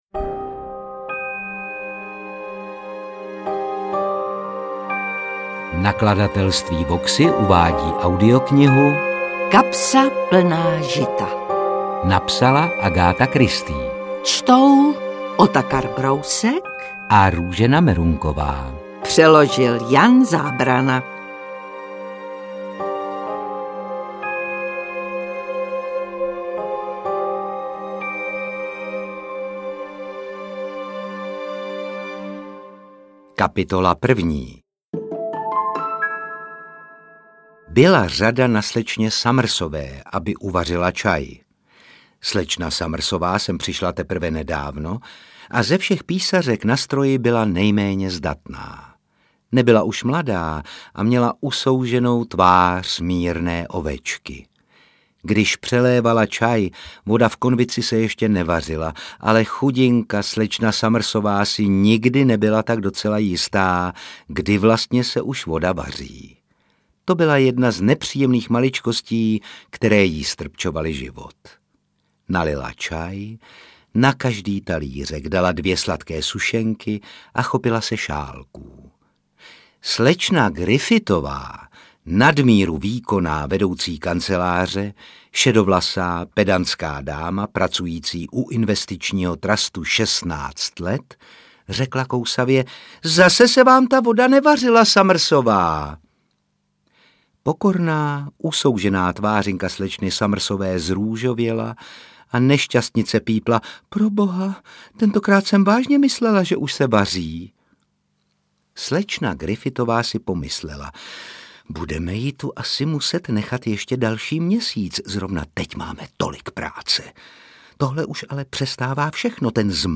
AudioKniha ke stažení, 28 x mp3, délka 8 hod. 4 min., velikost 453,9 MB, česky